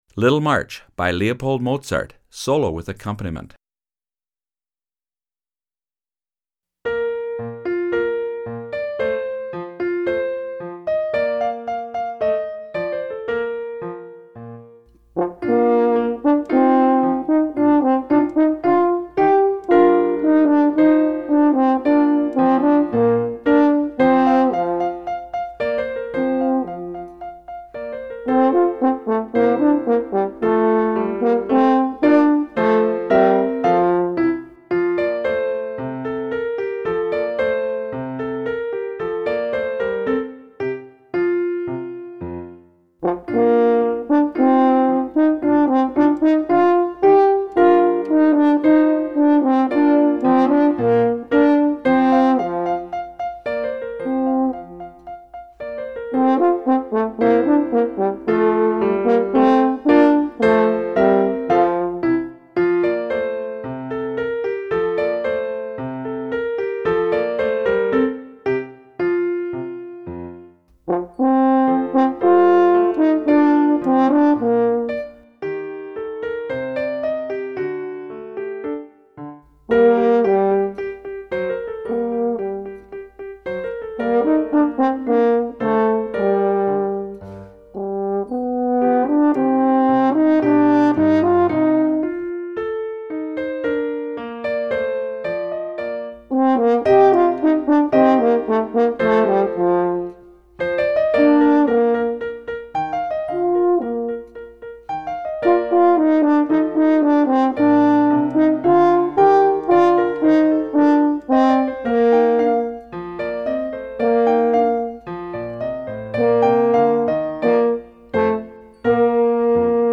Performance Tempo
French Horn
French Horn Solo with Piano Accompaniment
Little-March-French-Horn-Solo-With-Acc.mp3